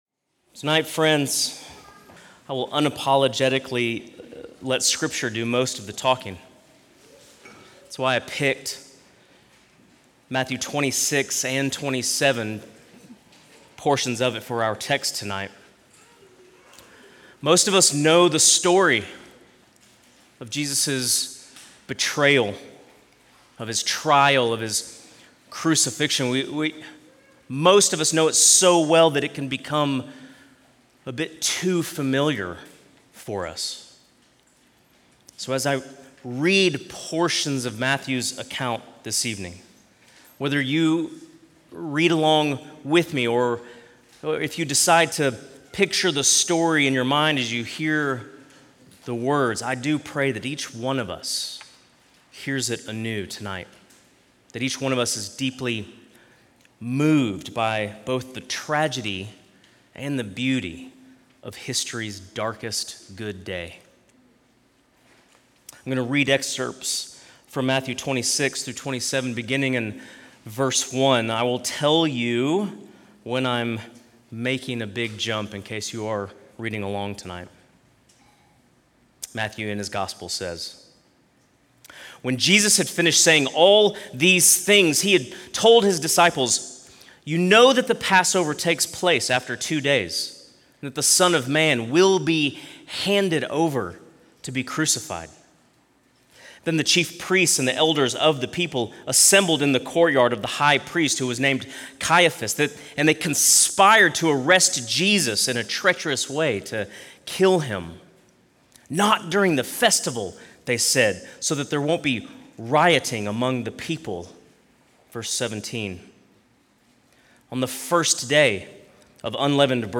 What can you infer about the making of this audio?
good_friday_2026.mp3